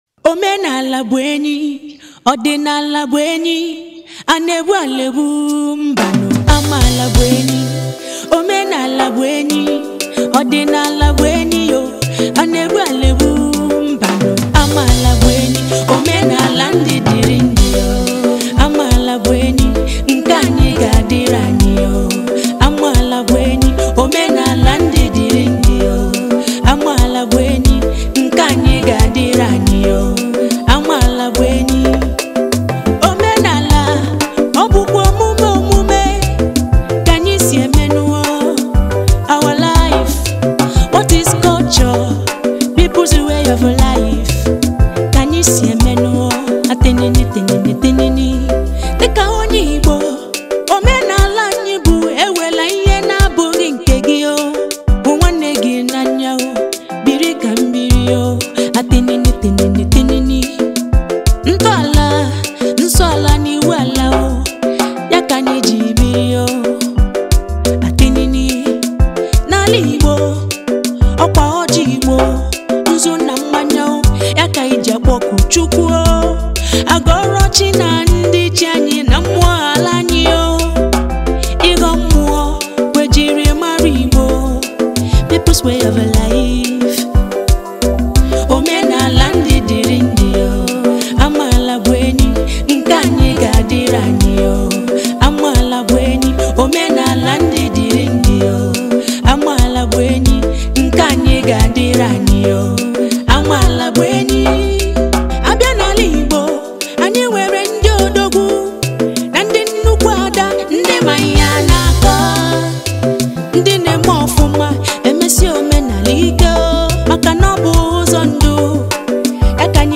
January 24, 2025 Publisher 01 Gospel 0